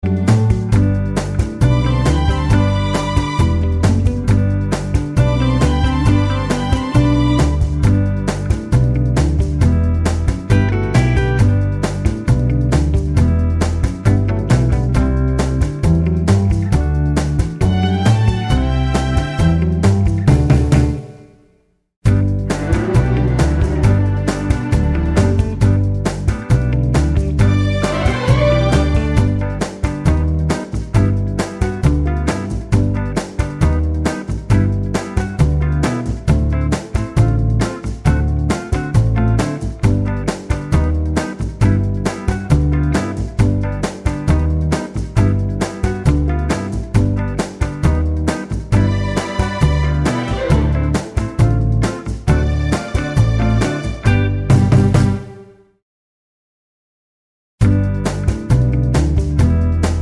no Backing Vocals Oldies (Female) 2:32 Buy £1.50